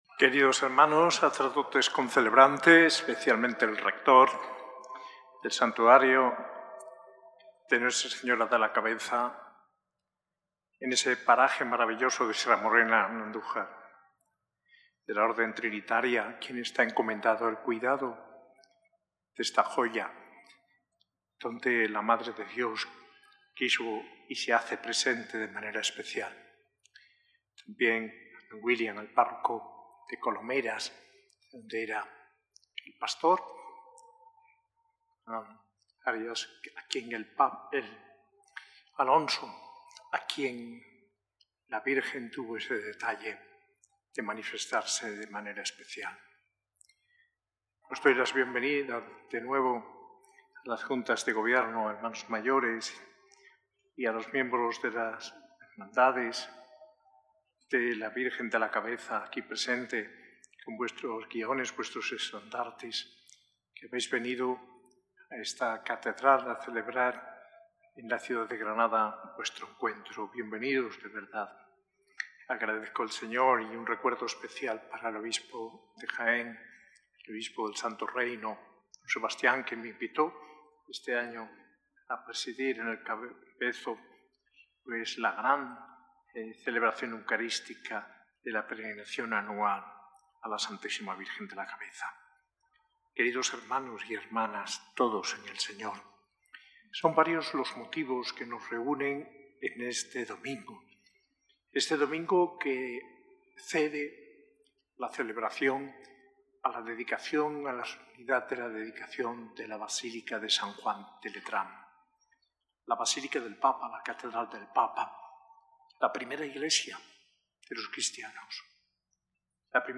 Homilía de Mons. José María Gil Tamayo, arzobispo de Granada, en la Fiesta de la dedicación de la Basílica de Letrán, el 9 de noviembre de 2025, en la S. A. I. Catedral de Granada.